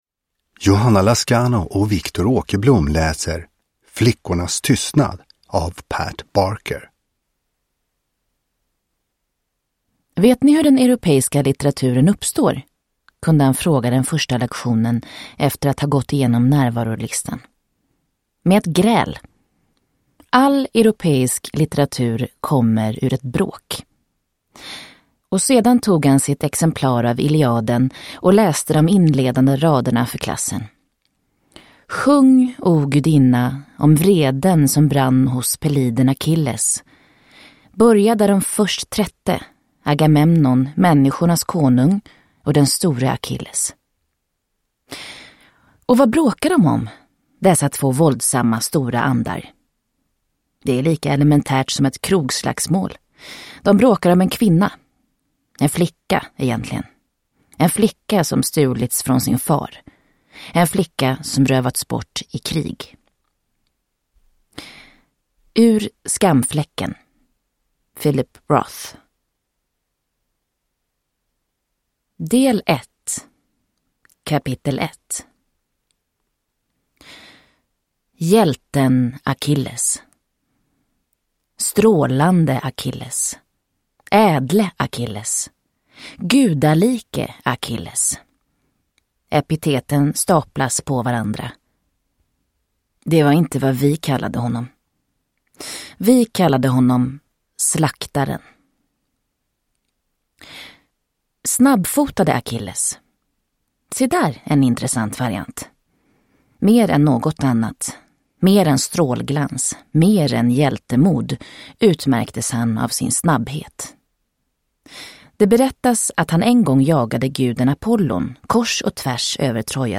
Flickornas tystnad – Ljudbok – Laddas ner